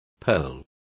Complete with pronunciation of the translation of purls.